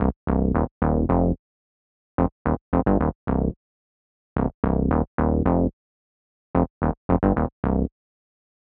30 Bass PT3.wav